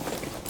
Gear Rustle Redone
tac_gear_7.ogg